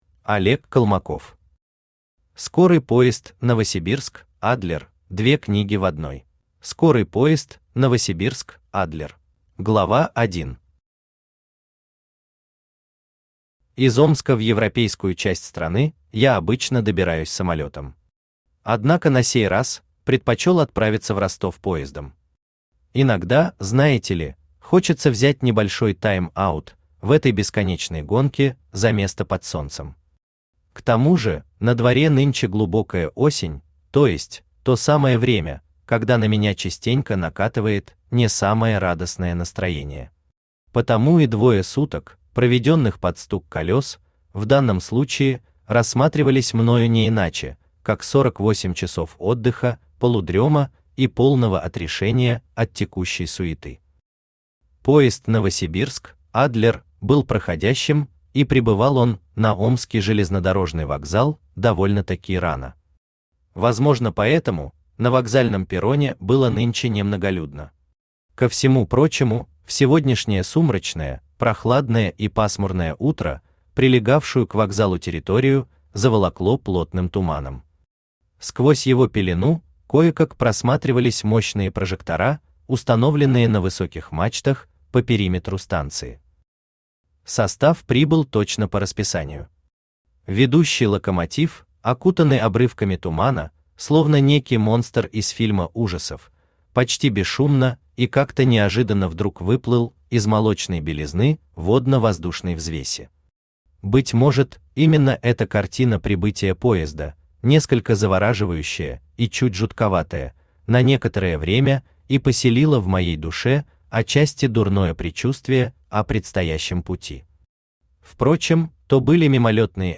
Aудиокнига Скорый поезд «Новосибирск – Адлер» (две книги в одной) Автор Олег Колмаков Читает аудиокнигу Авточтец ЛитРес.